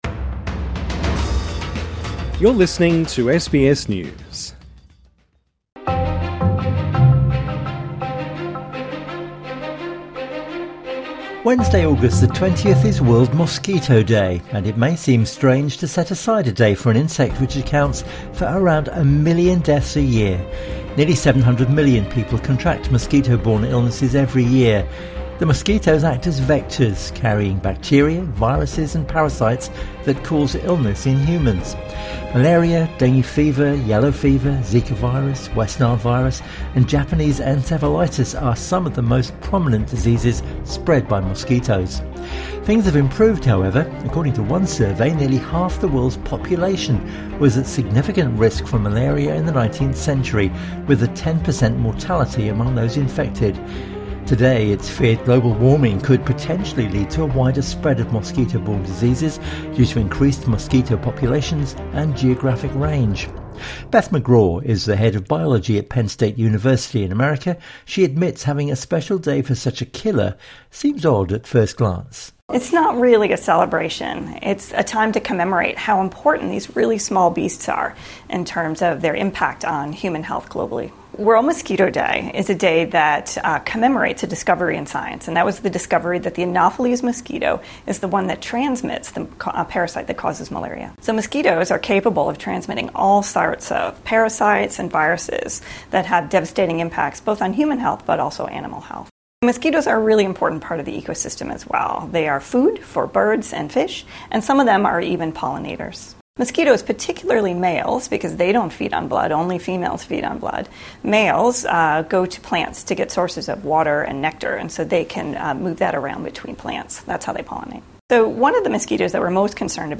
INTERVIEW: This killer causes a million deaths - and gets a special day?